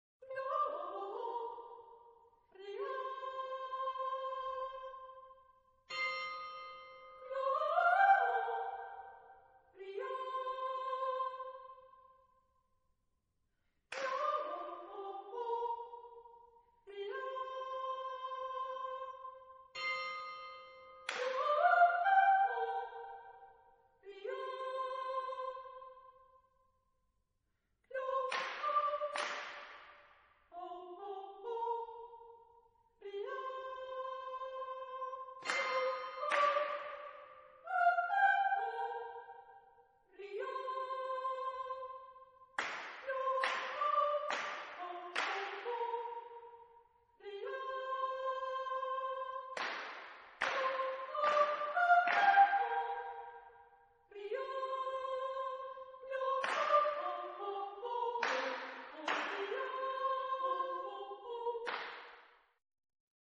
Genre-Style-Form: Secular ; Folk music ; Contemporary
Mood of the piece: melancholic ; joyous ; dancing
Type of Choir: SATB  (4 mixed voices )
Tonality: various